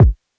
Kicks
DJP_KICK_ (127).wav